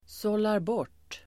Ladda ner uttalet
Uttal: [sålarb'år:t]